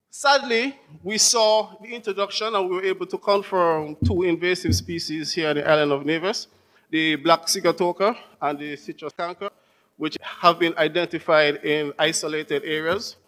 That was Director of Agriculture on Nevis, Mr. Randy Elliott.